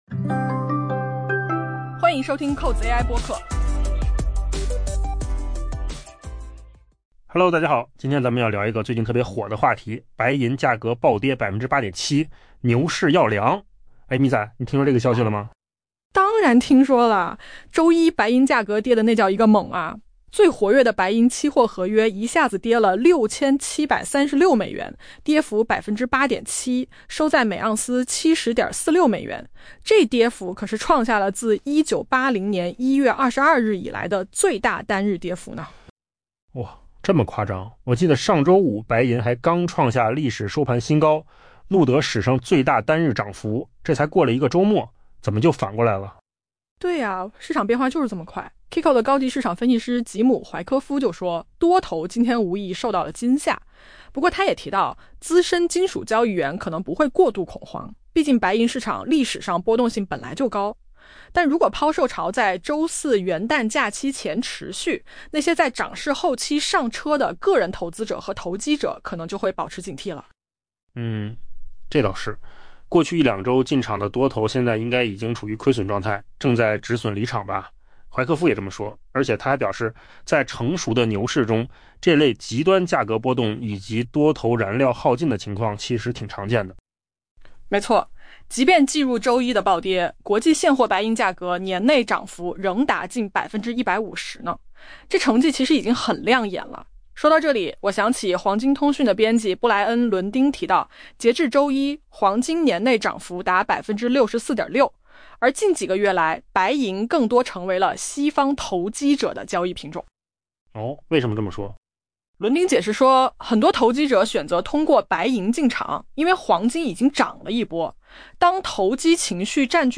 AI 播客：换个方式听新闻 下载 mp3 音频由扣子空间生成 周一白银价格大幅下挫，引发市场对这一贵金属年内惊艳涨势可能失速的担忧。